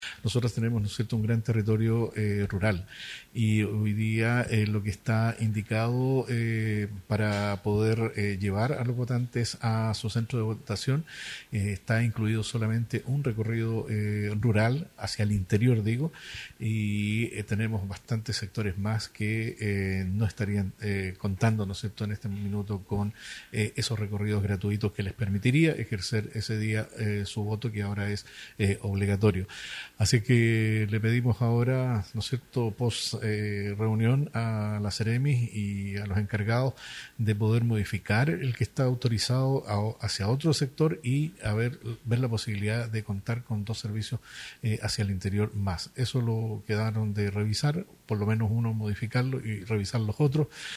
Escucha aquí la entrevista completa con la Seremi de Transportes y Telecomunicaciones en Biobío, Claudia García Lima.